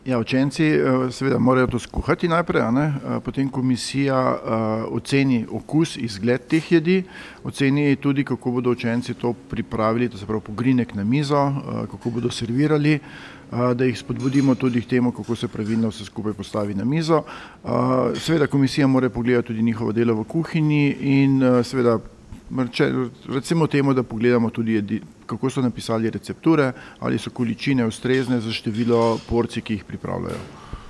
V Slovenj Gradcu danes poteka prvo od sedmih regijskih tekmovanj Zlata kuhalnica, priljubljeno tekmovanje osnovnošolskih kuharskih talentov.